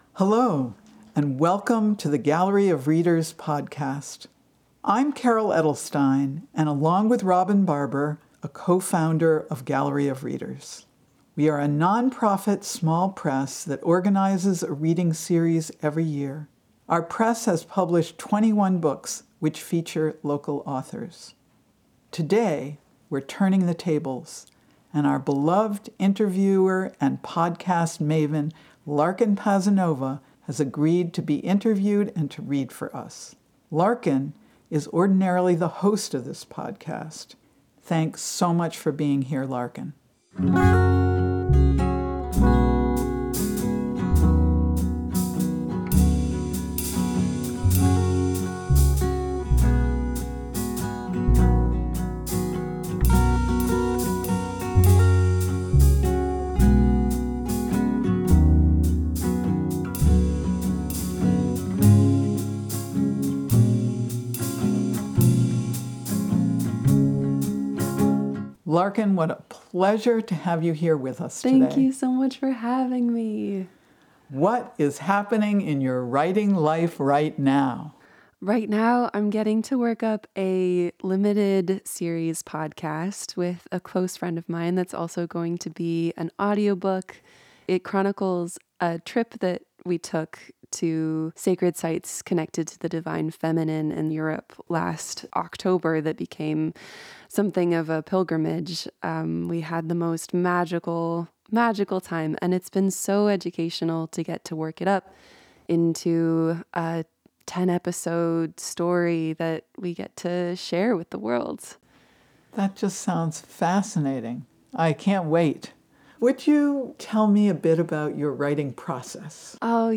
is interviewed